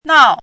“闹”读音
nào
闹字注音：ㄋㄠˋ
国际音标：nɑu˥˧